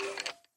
Minecraft Skelton